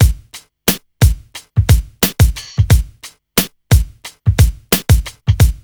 RNB89BEAT1-R.wav